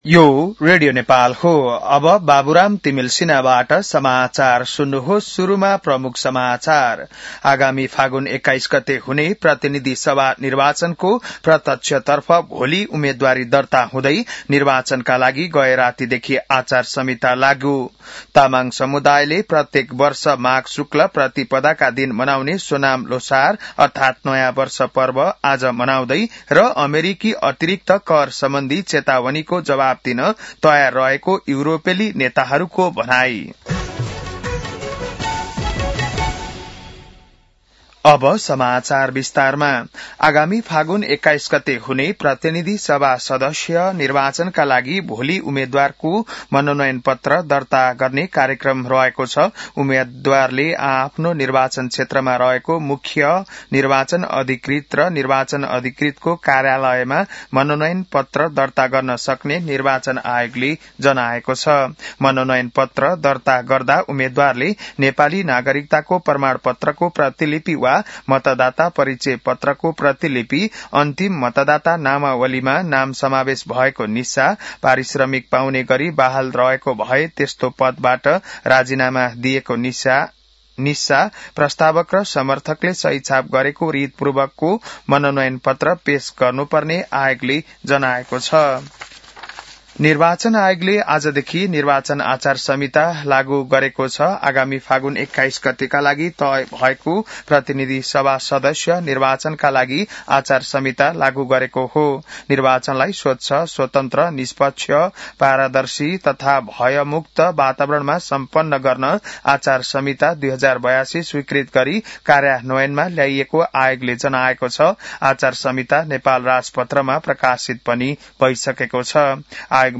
बिहान ९ बजेको नेपाली समाचार : ५ माघ , २०८२